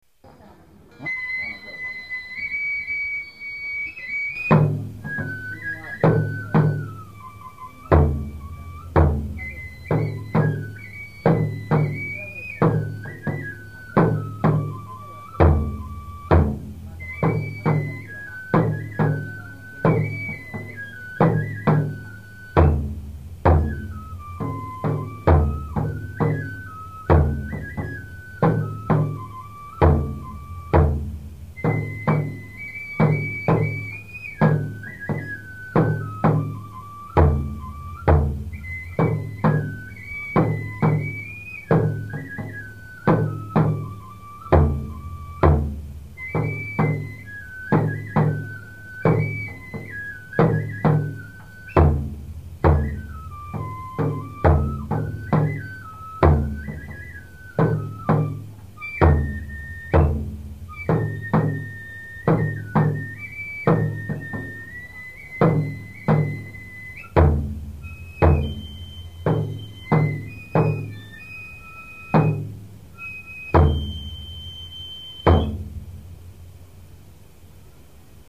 津賀田神社神楽の公開録音会　（昭和３３年１０月２８日）